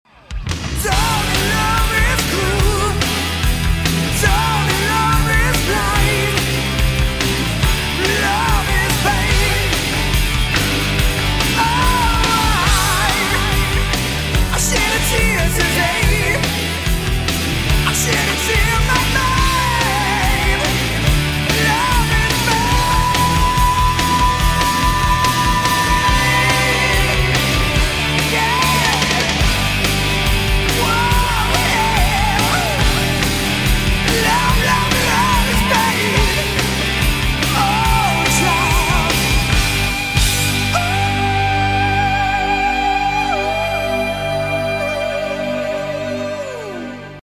heavy Metal
power metal